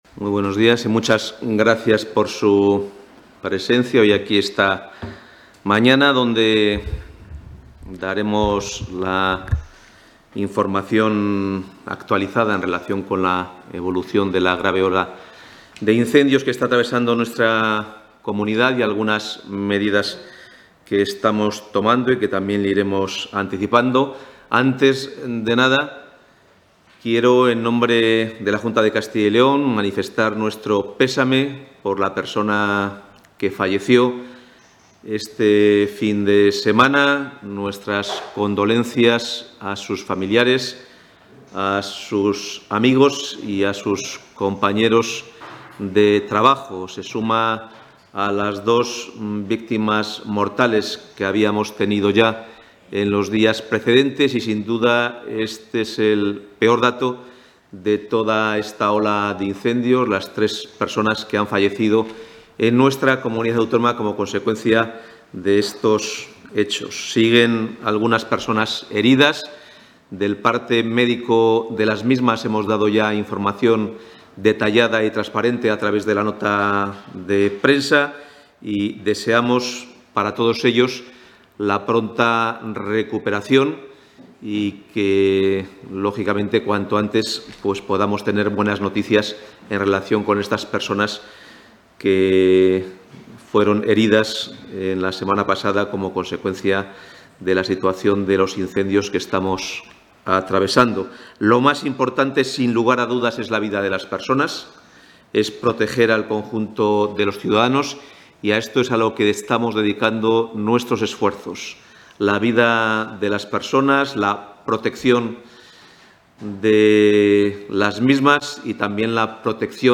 Intervención del portavoz.
Intervención del portavoz Reunión de seguimiento de la situación de los incendios Reunión de seguimiento de la situación de los incendios Reunión de seguimiento de la situación de los incendios Rueda de prensa para informar de la reunión de seguimiento